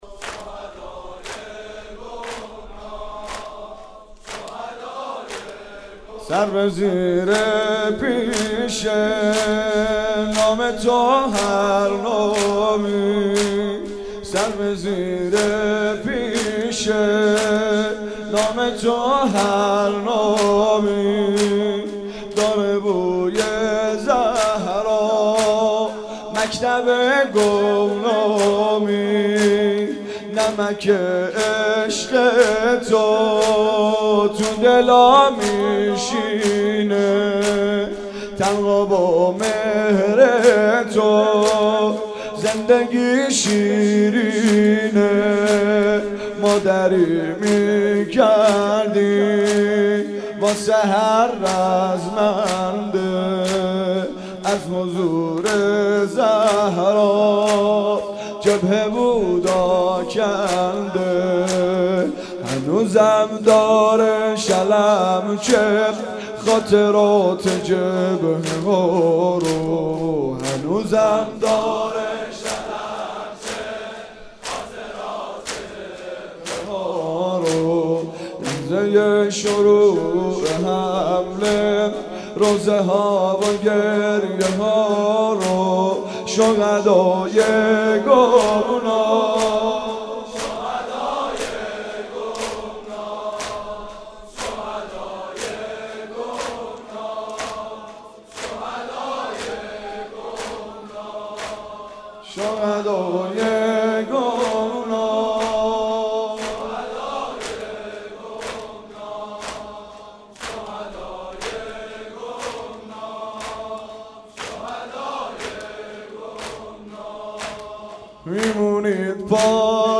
زمینه زیبا برای شهدای گمنام